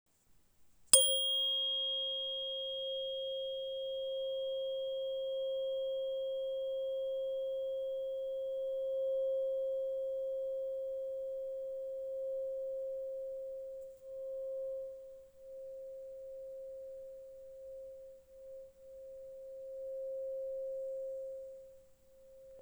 Terapeutická ladička 531 Hz Genesis hliník CZ
Rozeznívá se gumovou paličkou nebo o aktivátor.
Její zvuk je jemný a příjemný.
Terapeutické ladičky z naší české dílny jsou vyrobené z prvotřídního hliníku, který poskytuje výjimečně čistou a dlouhotrvající vibraci.